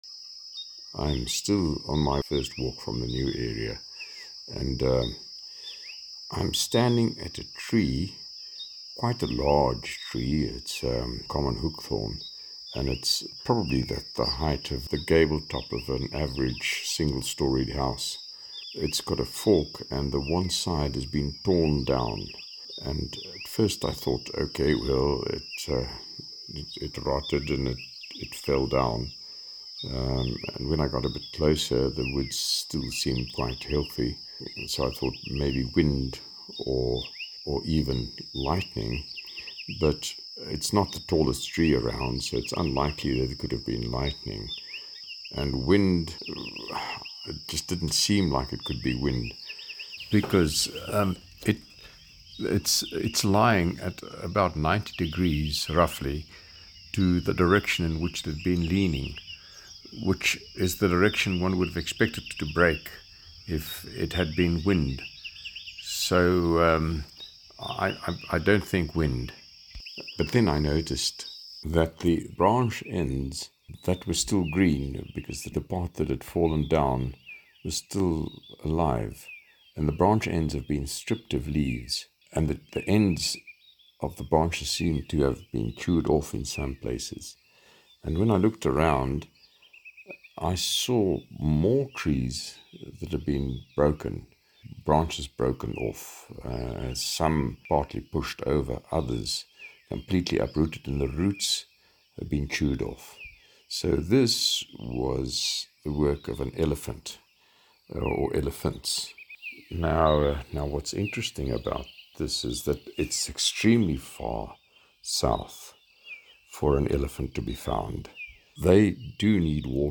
More veld walking.
Continuation-of-morning-walk-from-New-camp.mp3